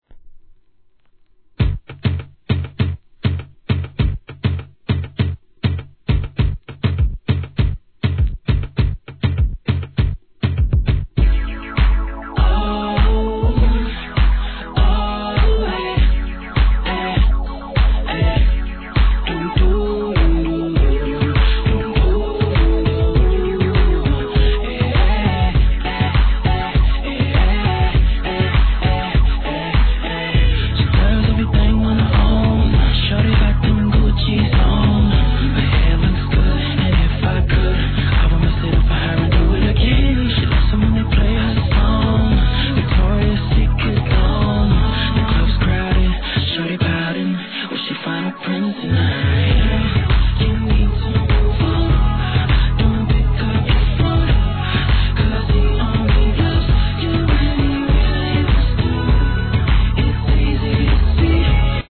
HIP HOP/R&B
極上美メロソングだけを収録したラグジュアリーR&Bコンピの必須アイテムシリーズ第33弾が登場!!